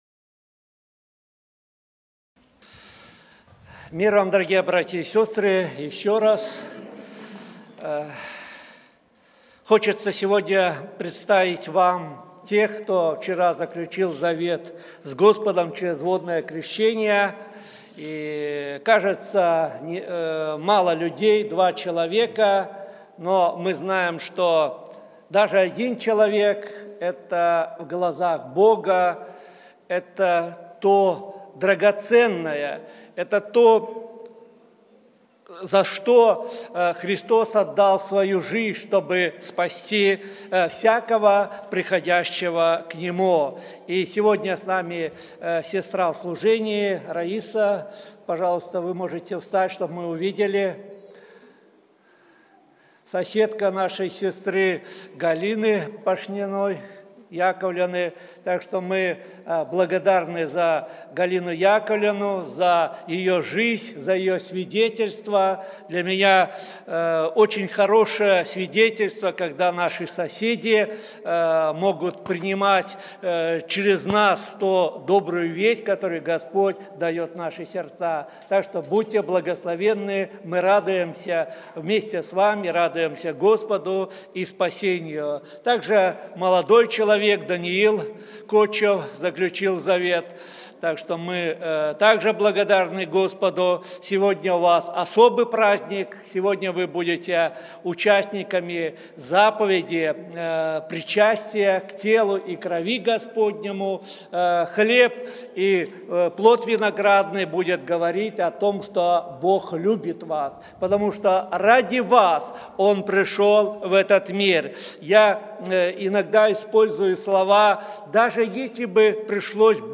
Церковь евангельских христиан баптистов в городе Слуцке